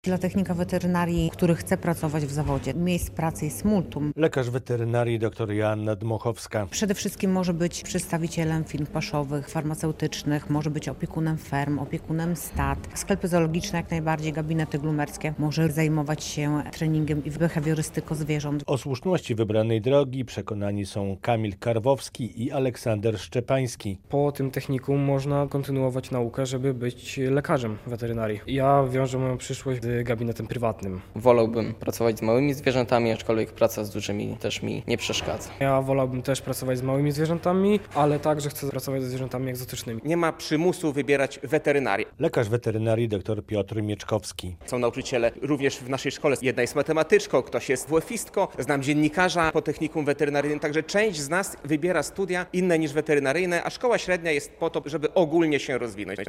Dzień weterynarii w "Wecie" - relacja